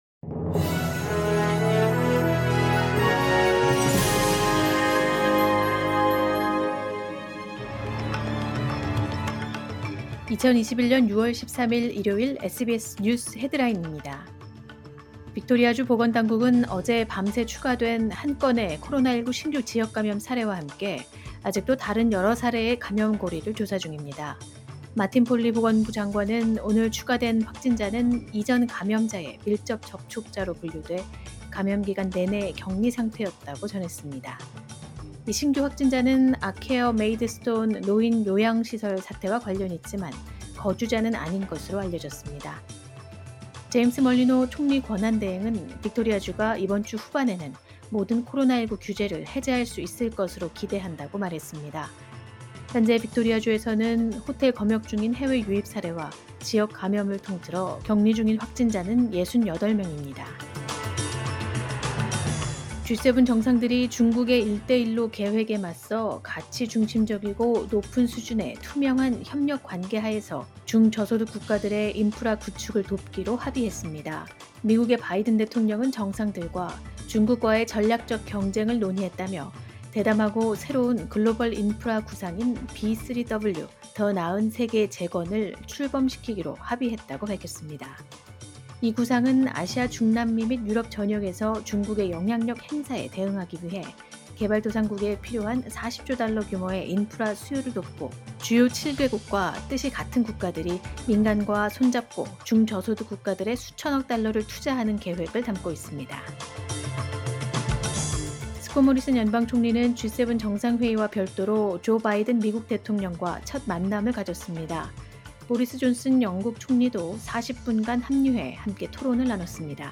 2021년 6월 13일 일요일 SBS 뉴스 헤드라인입니다.